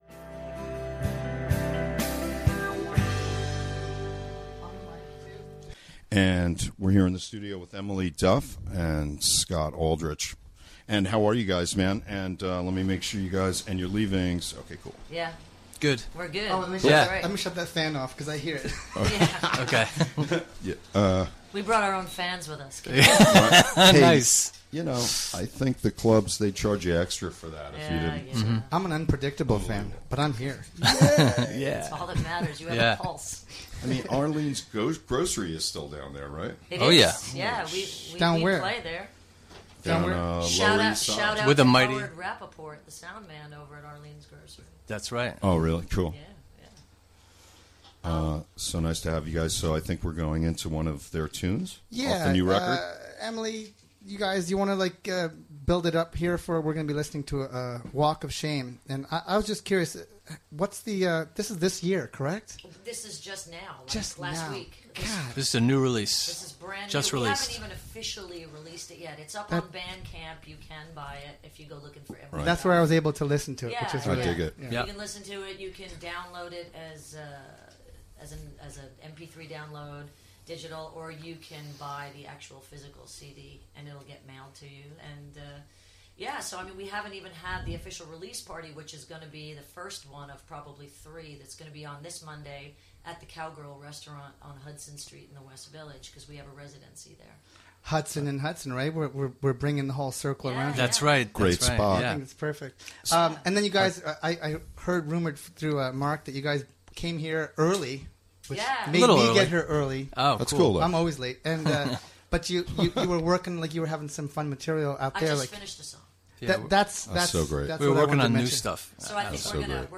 Interview conducted during the WGXC Afternoon Show.